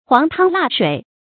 黃湯辣水 注音： ㄏㄨㄤˊ ㄊㄤ ㄌㄚˋ ㄕㄨㄟˇ 讀音讀法： 意思解釋： 泛指飲食。